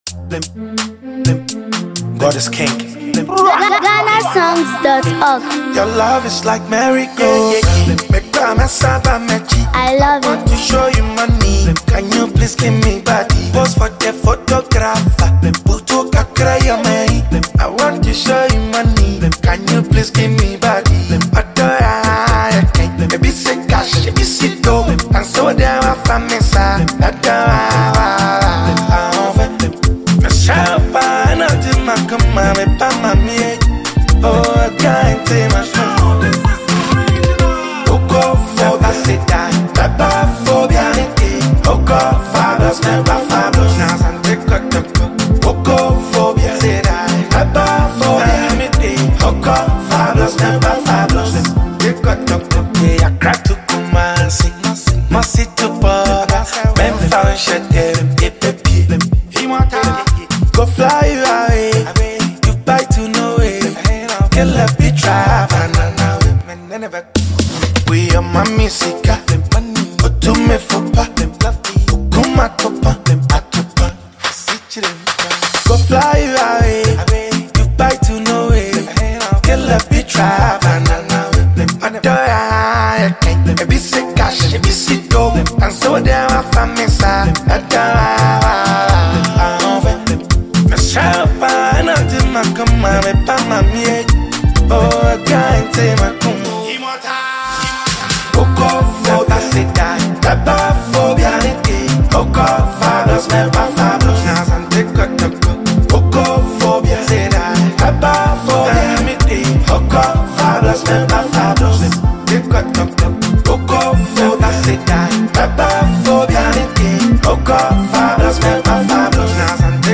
Ghanaian rap sensation
blends deep lyrics with a groovy beat
• Genre: Hip-Hop/Rap